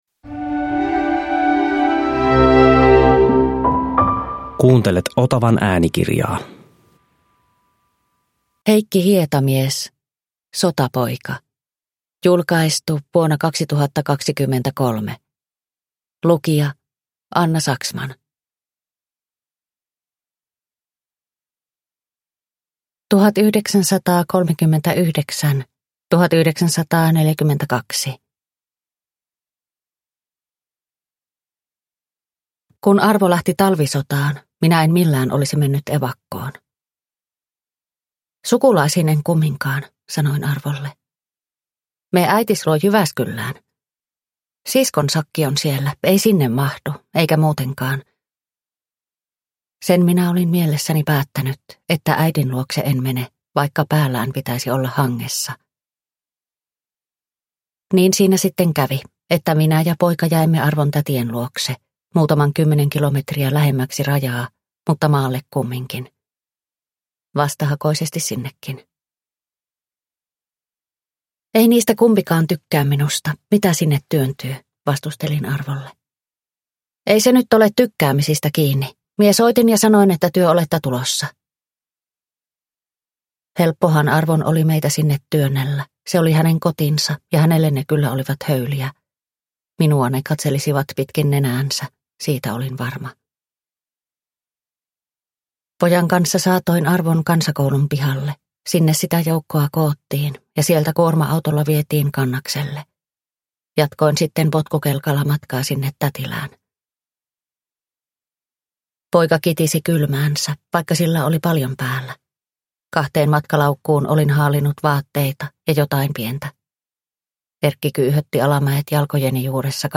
Sotapoika – Ljudbok – Laddas ner